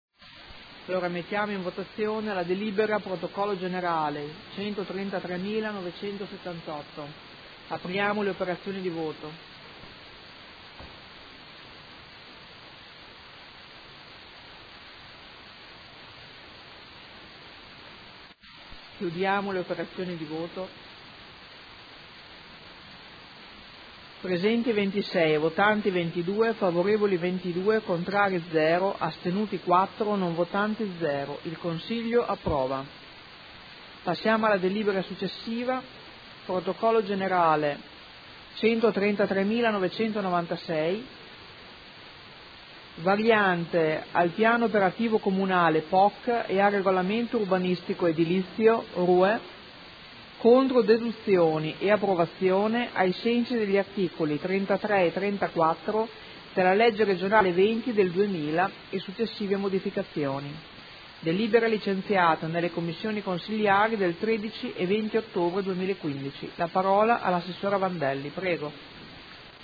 Presidente
Seduta del 22 ottobre.